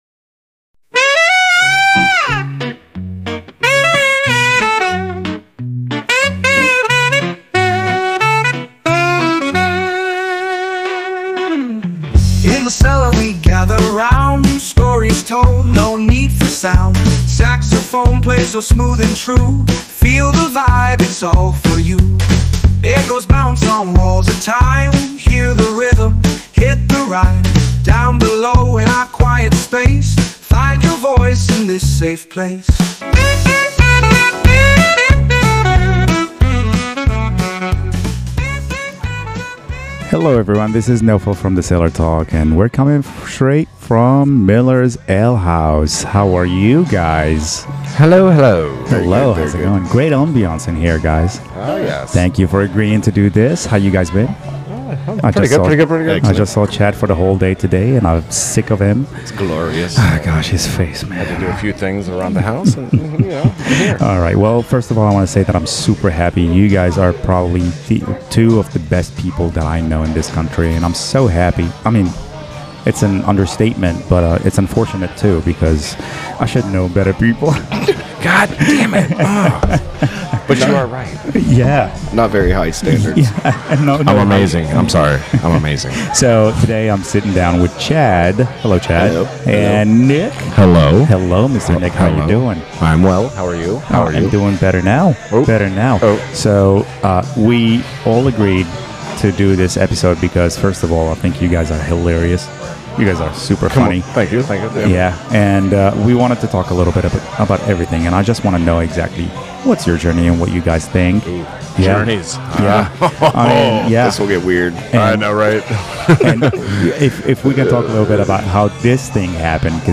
A special thanks to Miller’s Ale House in Annapolis for letting us record there.